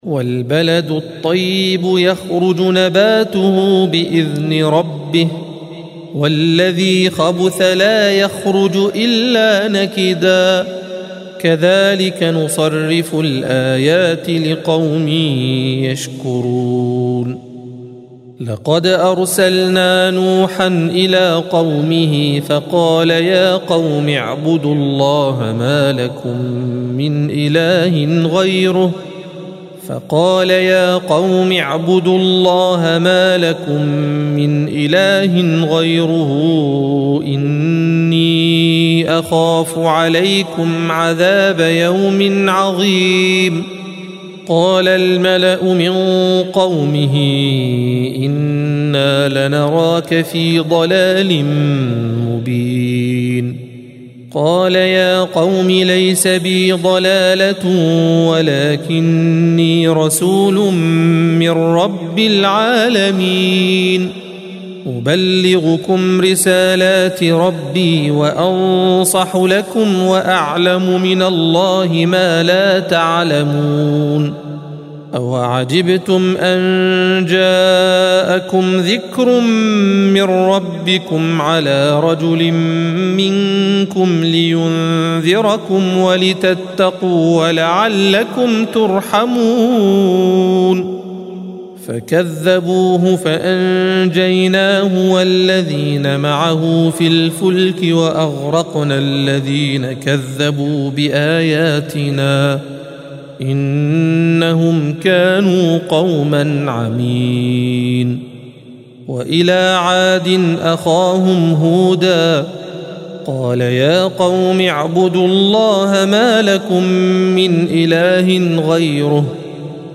الصفحة 158 - القارئ